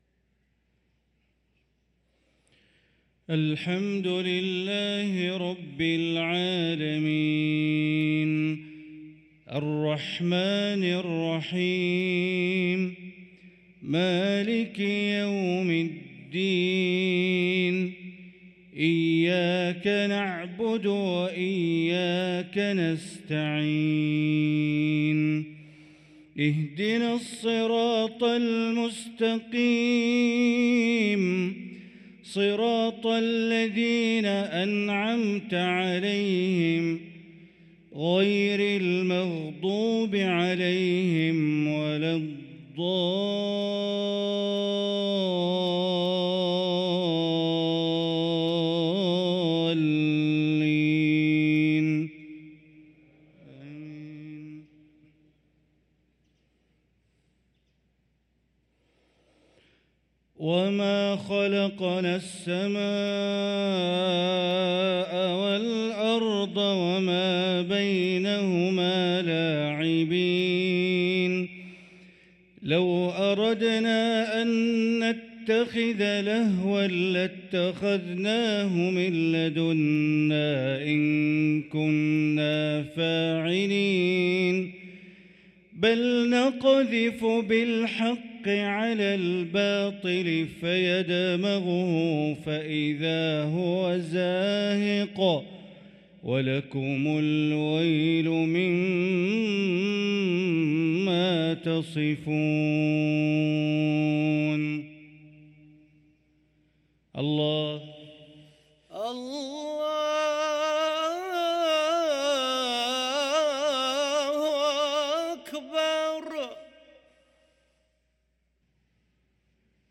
صلاة المغرب للقارئ بندر بليلة 2 جمادي الأول 1445 هـ